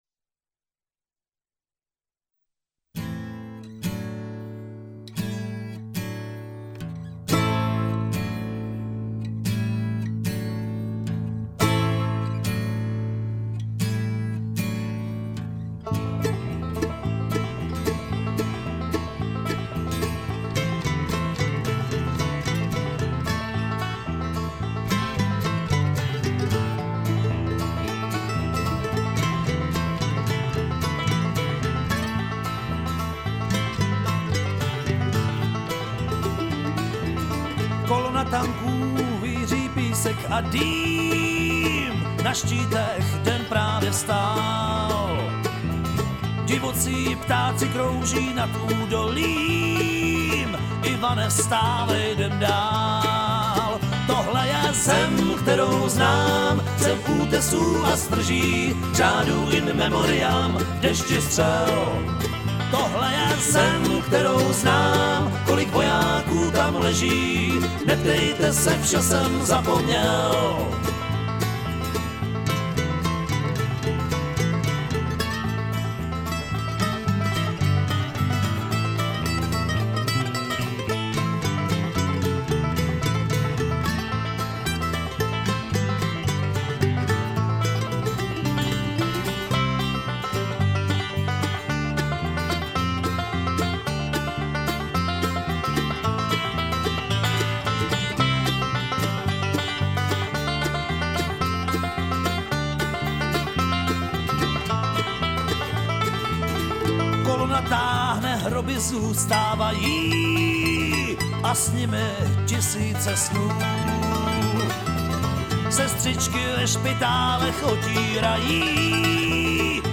country a folkové
kytara
baskytara
banjo